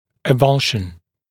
[ə’vʌlʃn][э’валшн]экзерез, авульсия, вывих